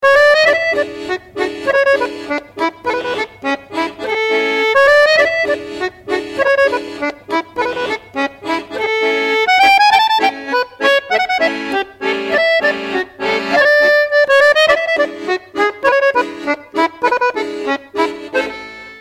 Nachrichtentöne